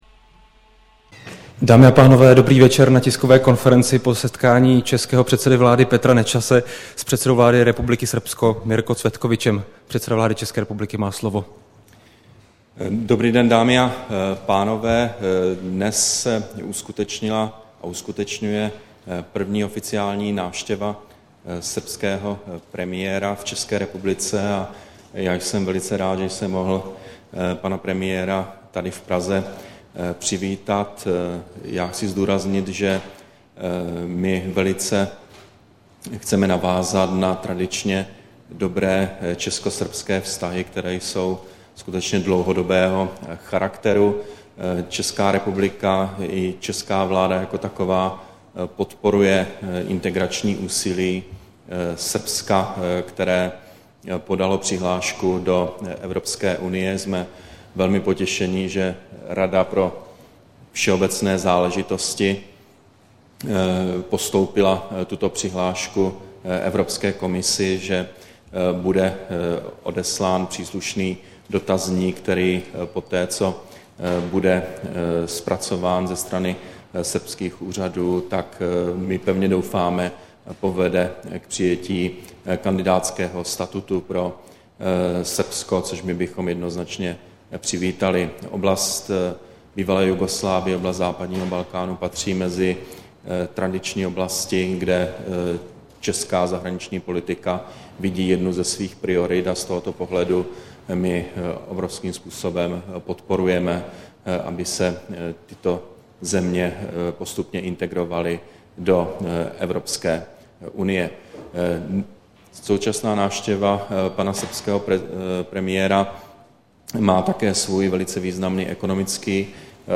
Tisková konference premiéra Petra Nečase a předsedy vlády Srbska Mirka Cvetkoviće, 15. listopadu 2010